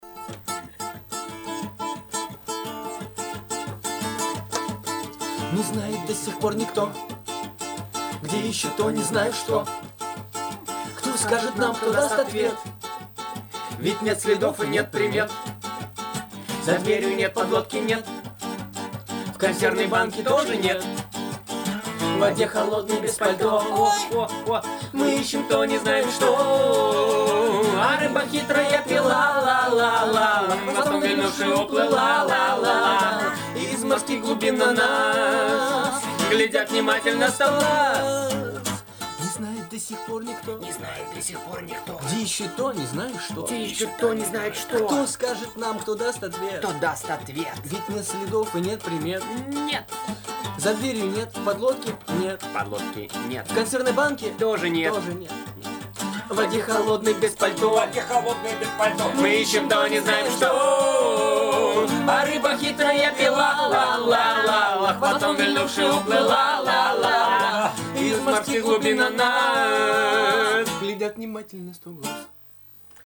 Треки, исполненные вживую в Доме радио: